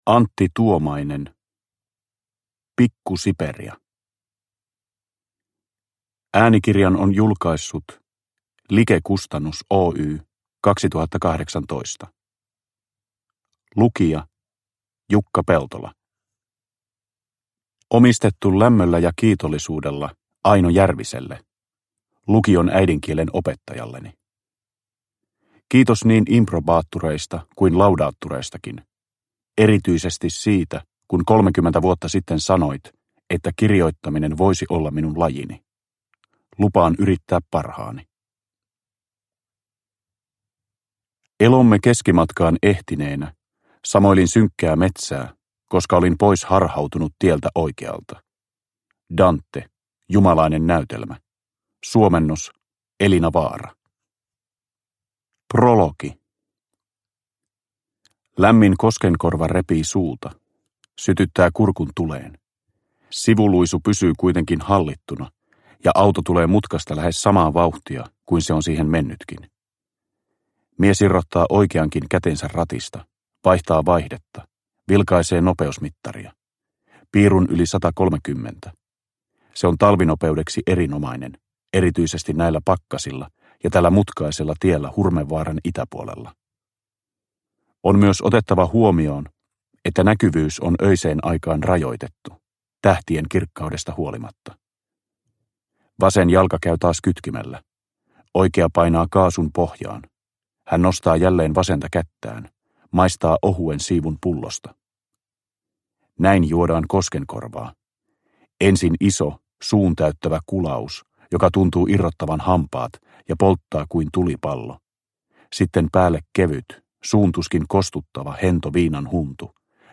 Pikku Siperia – Ljudbok – Laddas ner
Uppläsare: Jukka Peltola